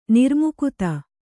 ♪ nirmukuta